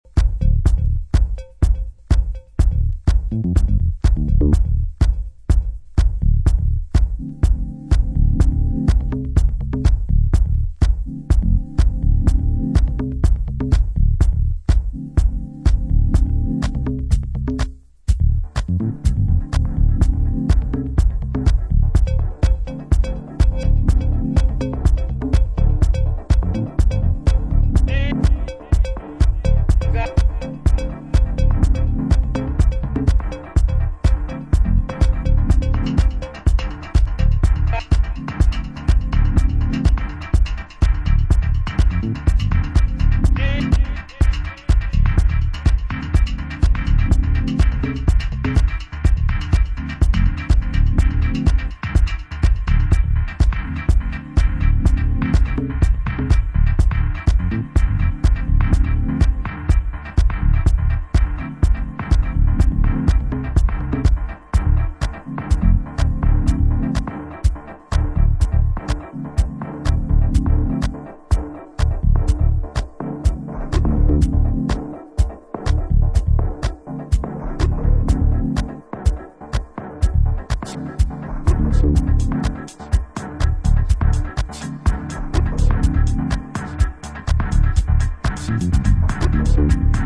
ダビーなエフェクト処理を施した生パーカッションを交えながらクールにビルドアップしていくヒプノティック・ディープ・ハウス